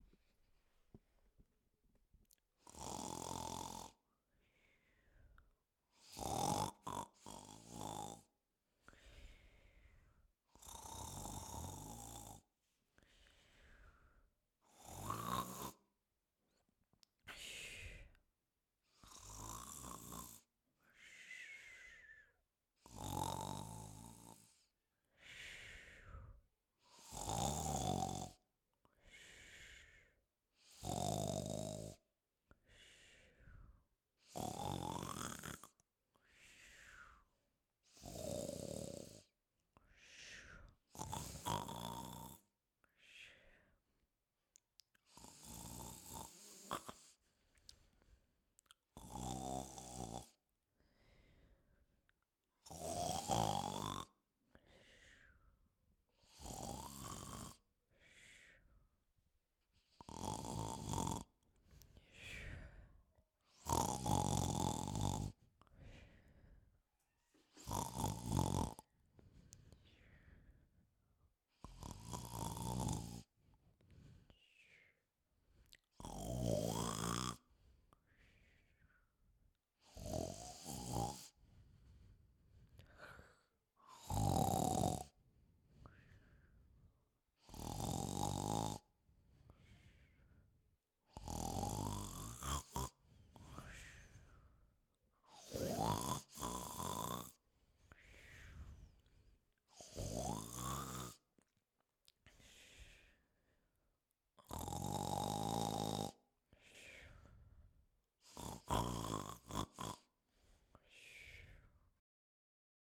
Cartoon snoring
Cartoon OWI Snoring sound effect free sound royalty free Movies & TV